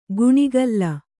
♪ guṇigalla